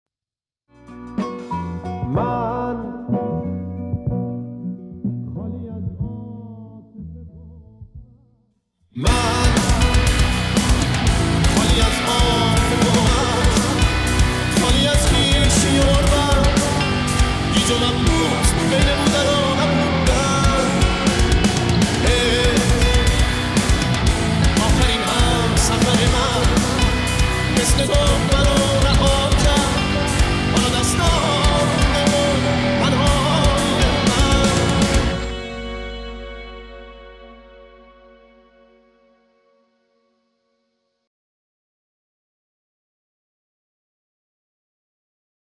سبک متال